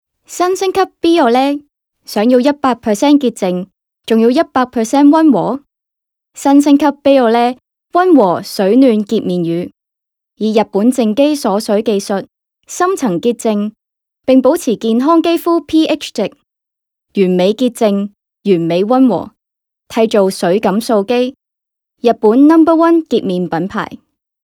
Advertisement (Shopping Mall) - Cantonese
Voix off